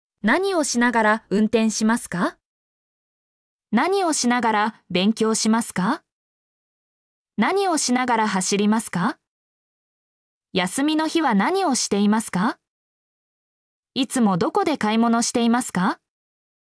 mp3-output-ttsfreedotcom-16_JggM7qx4.mp3